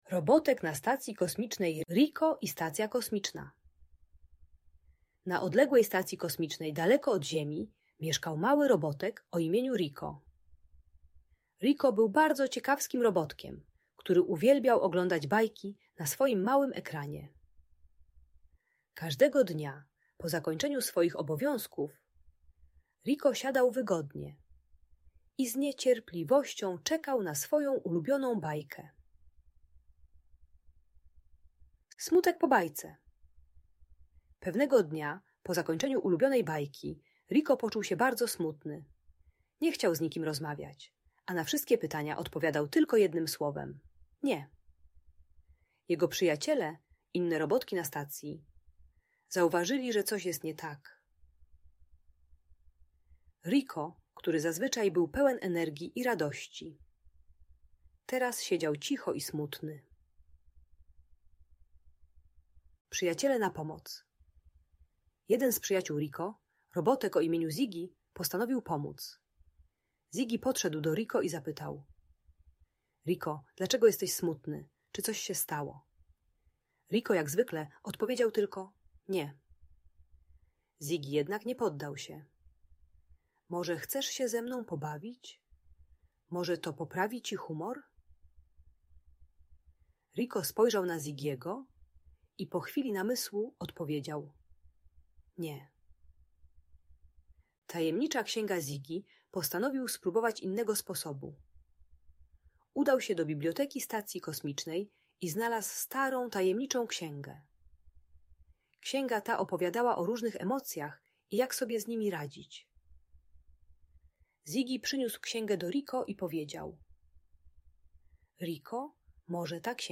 Opowieść o Robotku Riko na Stacji Kosmicznej - Audiobajka